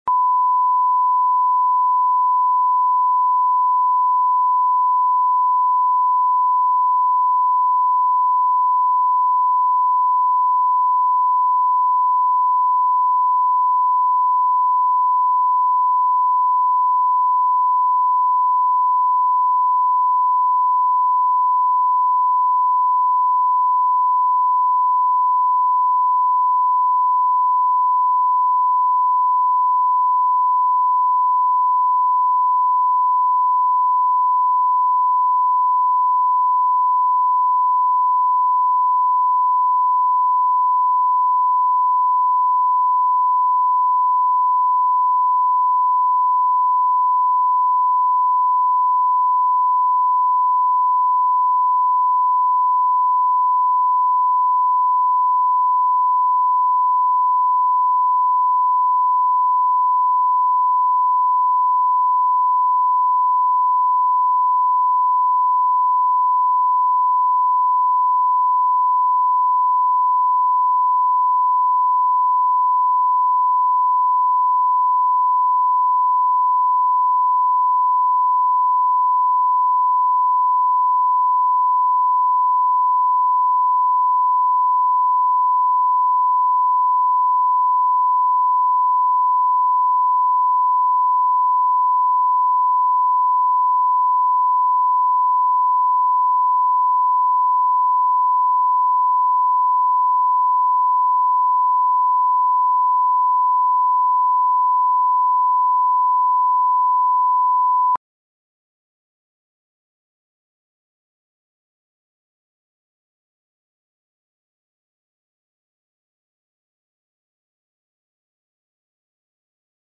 Аудиокнига Фотоаппарат